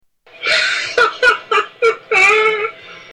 Laugh-BurtReynolds